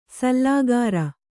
♪ sallāgāra